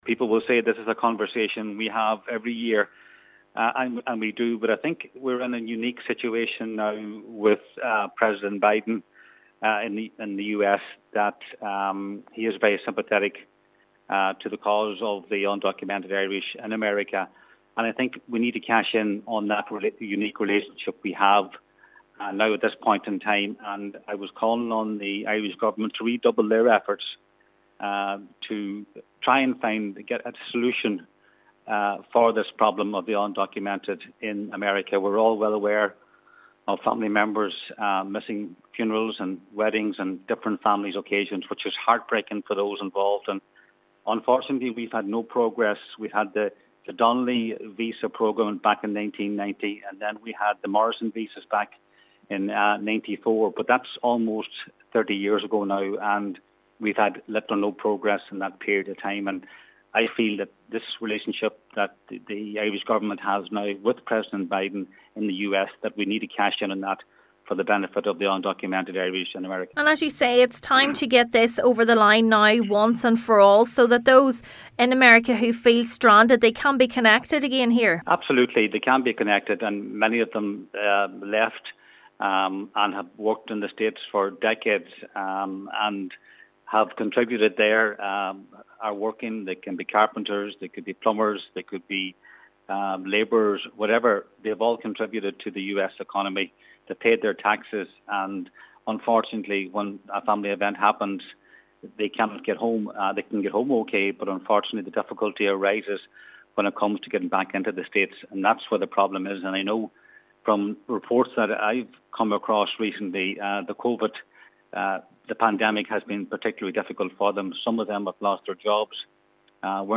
Senator Gallagher says the current US Administration has strong Irish-American influence and now is an opportune time to get the issue over the line once and for all: